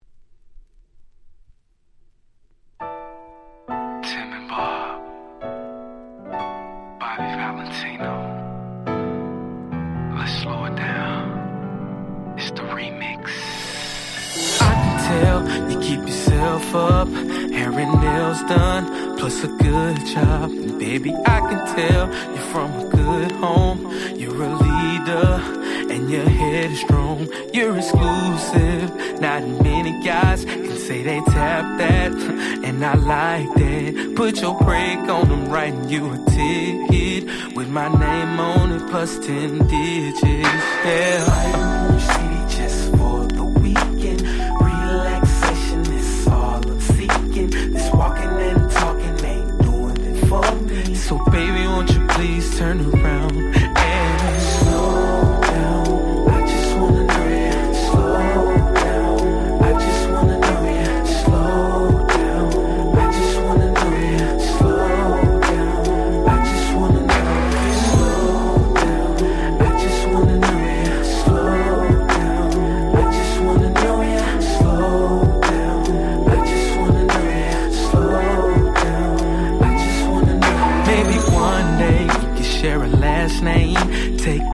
05' Smash Hit R&B !!